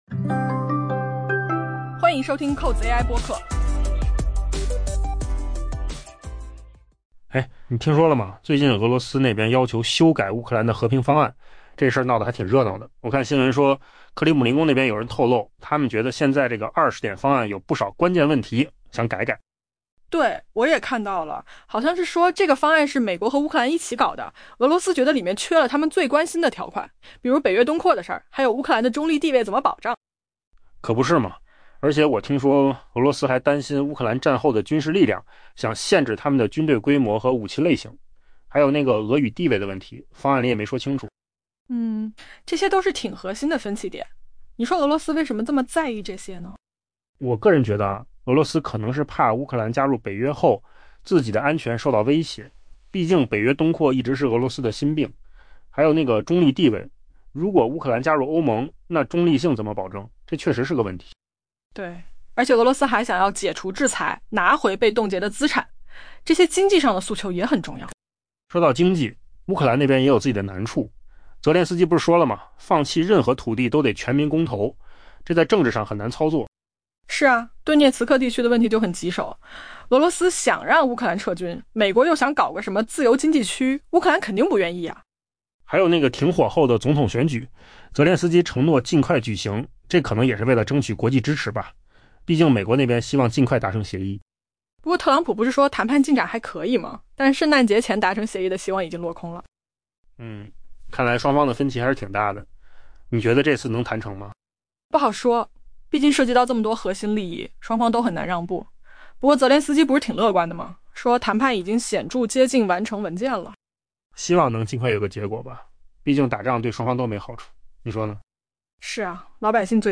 AI 播客：换个方式听新闻 下载 mp3 音频由扣子空间生成 据彭博社报道，一位接近克里姆林宫的人士透露，俄罗斯将寻求对美国制定的最新乌克兰和平方案进行关键修改， 包括对基辅的军事能力施加更多限制 。